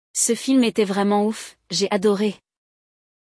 Neste episódio, você vai acompanhar um diálogo entre nativos que usam várias dessas expressões no dia a dia.